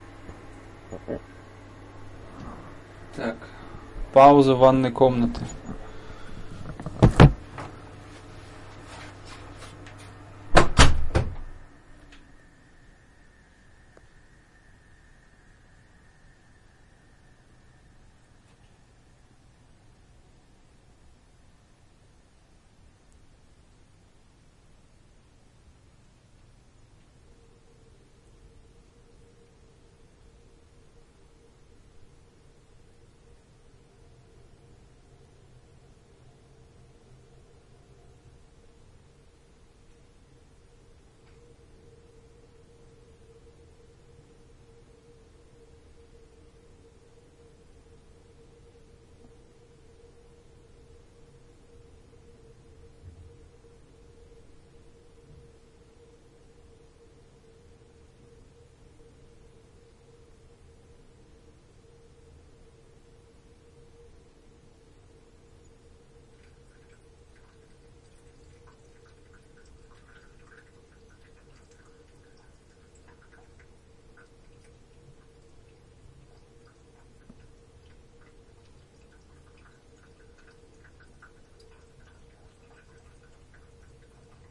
山区雨天房间色调
描述：一个来自roomtone的足够长的山区雨天的片段，在科罗拉多州的山区用猎枪式麦克风在portadat上录制
标签： 多雨 roomtone
声道立体声